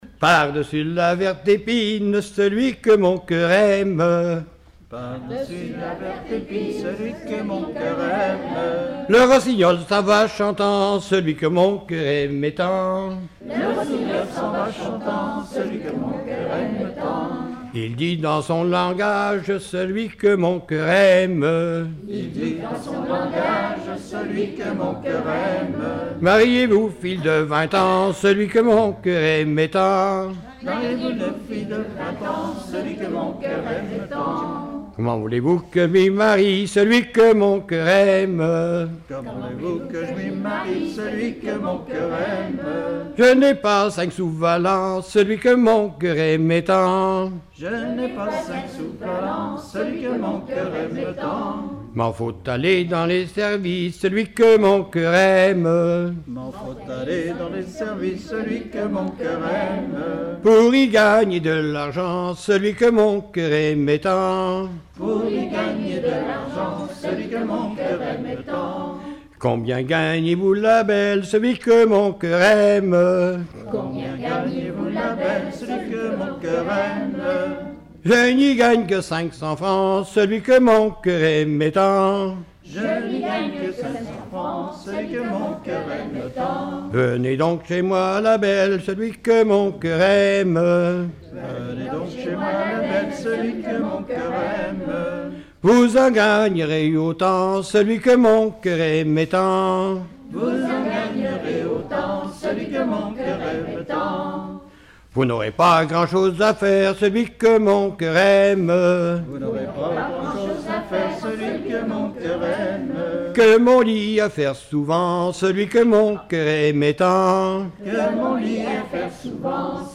danse : ronde : grand'danse
Collectif-veillée (1ère prise de son)
Pièce musicale inédite